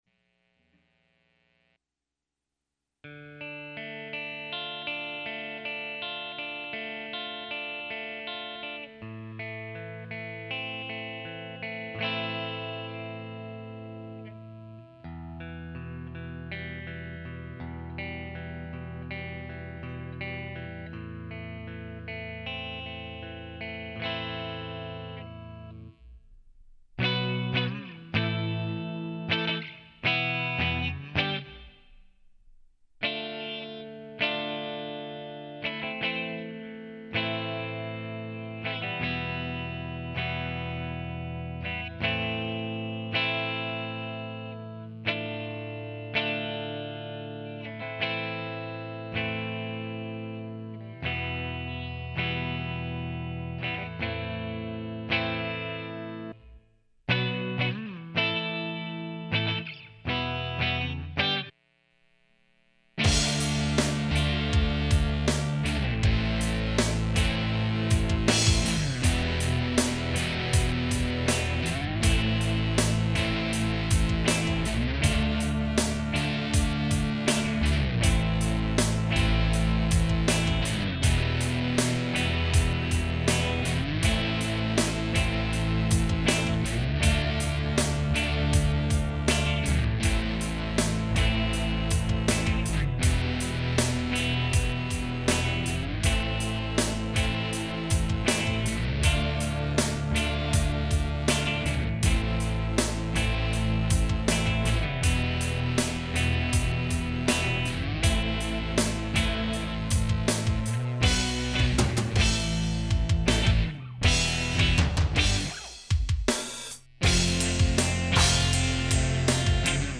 This is the first song I've tried to write music for after years of collaborating with a very talented musician friend. Unfortunately I can't sing so there are no vocals, but I'm hoping some of you can sit through 5:30 of instrumental. I've only been playing guitar since Christmas, so this is very amateur-ish. Also, I grew up with the '80s power ballads, so that's the direction this ended up taking.